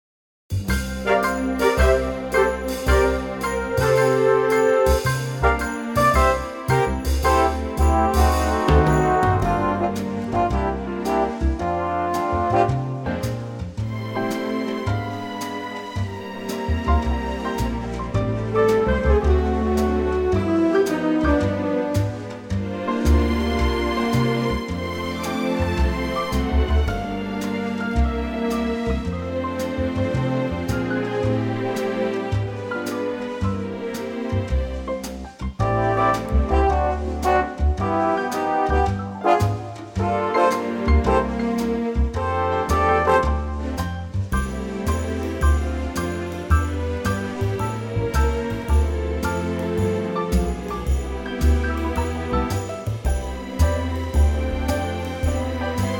key - F - vocal range - C to E